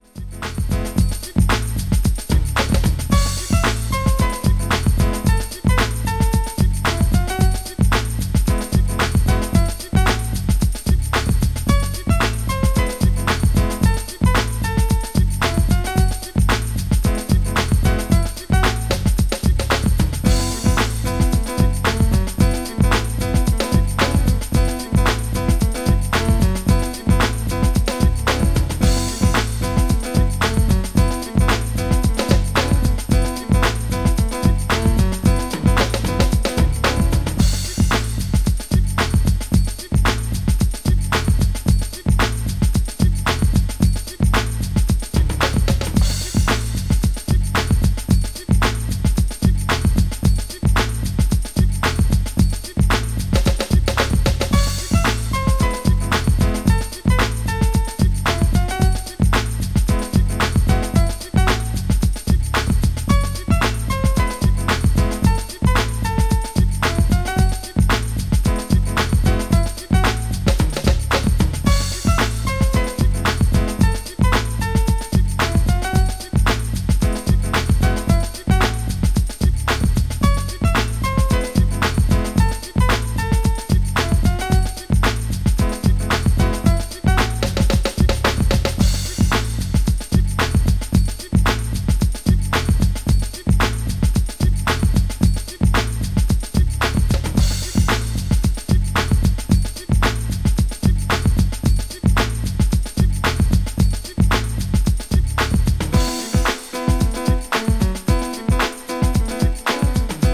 STYLE House